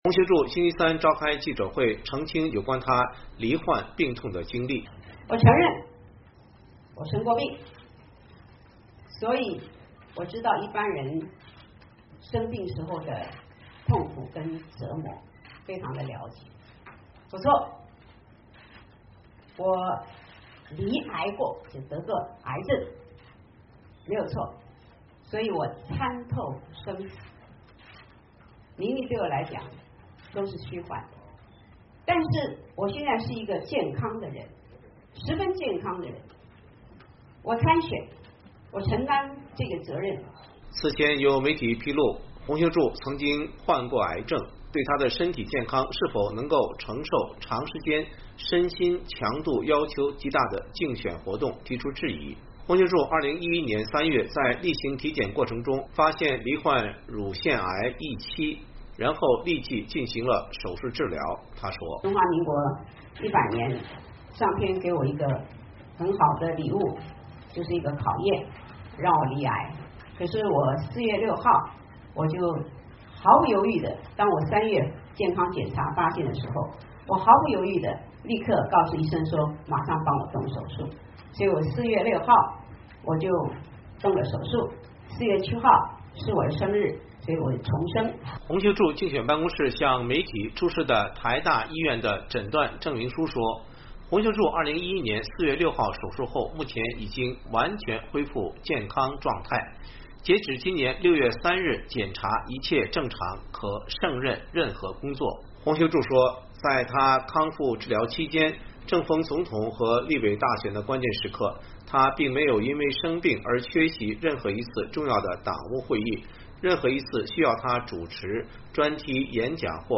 洪秀柱星期三召开记者会澄清有关她曾经罹患病痛的经历。